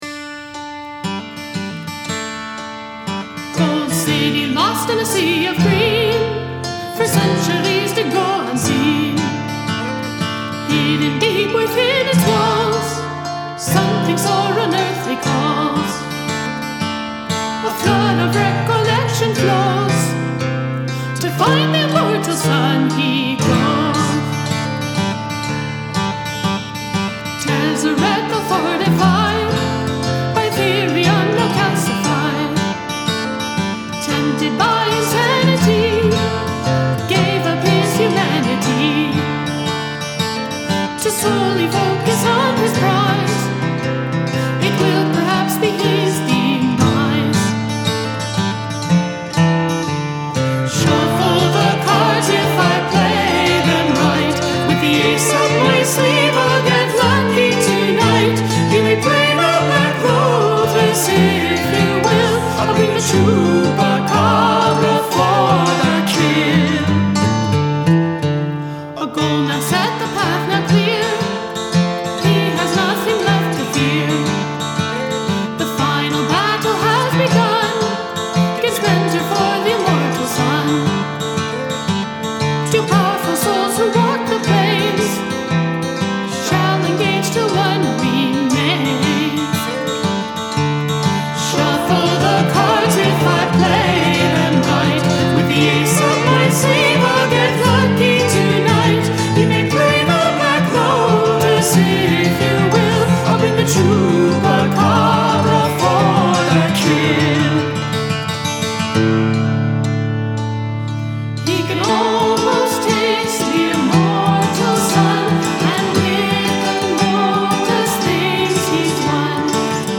Guitar
Hammered Dulcimer
This sounds beautifully clean and clear.
The interlocking vocals at the end of the song are great.